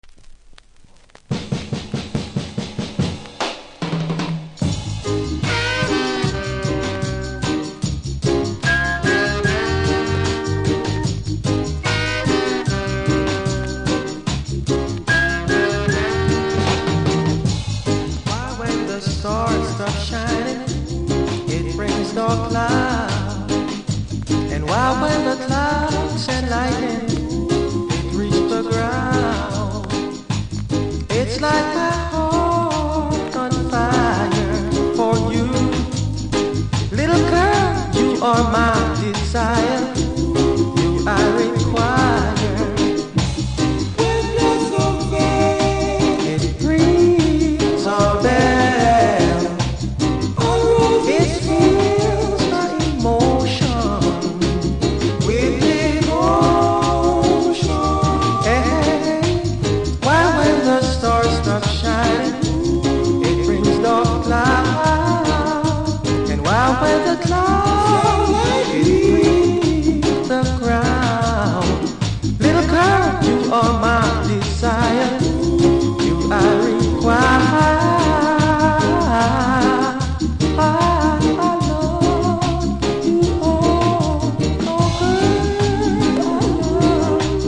キズもノイズも少なめなので試聴で確認下さい。